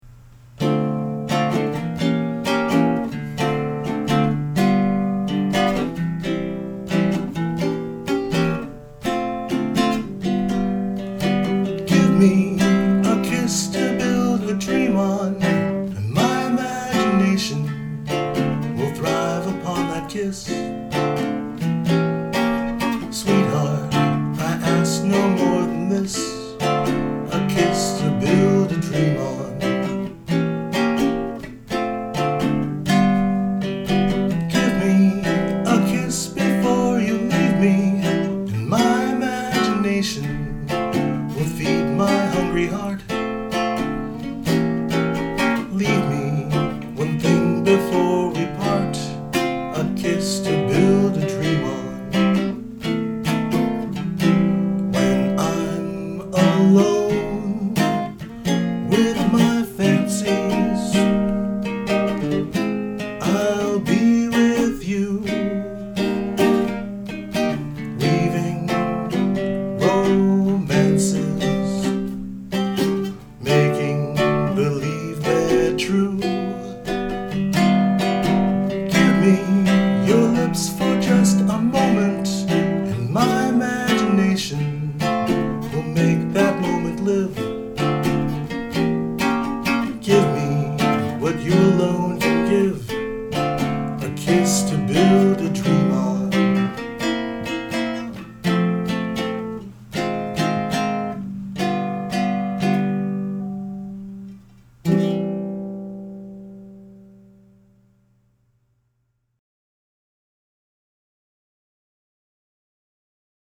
” with vocals
Filed under Music, Personal, Ukelele